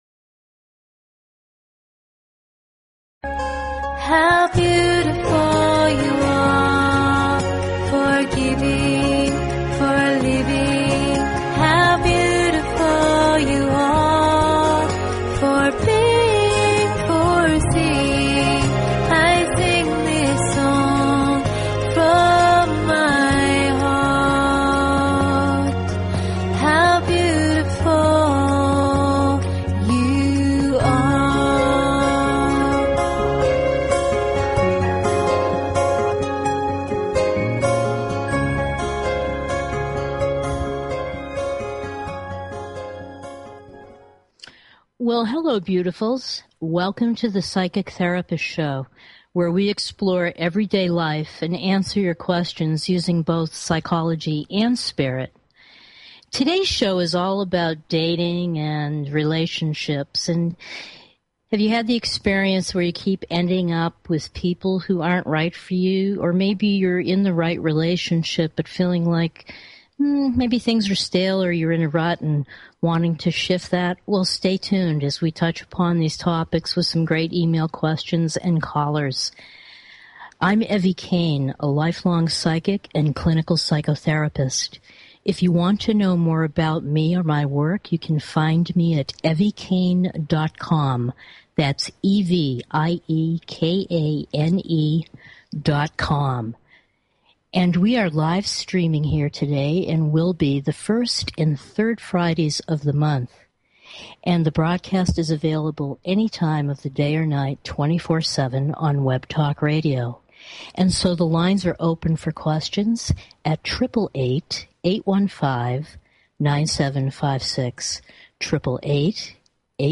Talk Show Episode, Audio Podcast, Psychic_Therapist_Show and Courtesy of BBS Radio on , show guests , about , categorized as